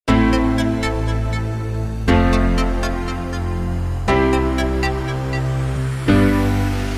• Качество: 321, Stereo
dance
без слов
пианино